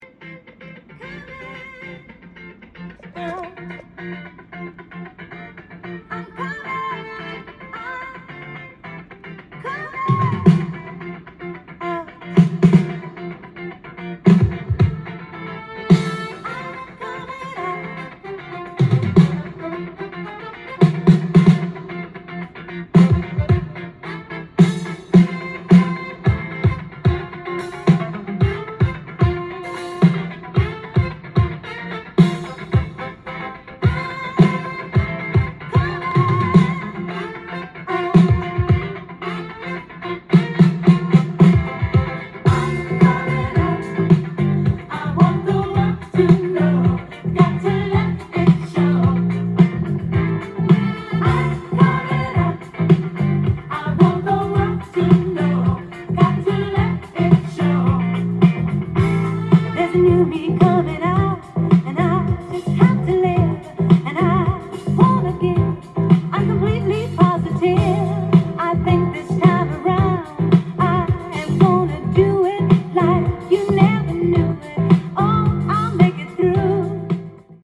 店頭で録音した音源の為、多少の外部音や音質の悪さはございますが、サンプルとしてご視聴ください。
音が稀にチリ・プツ出る程度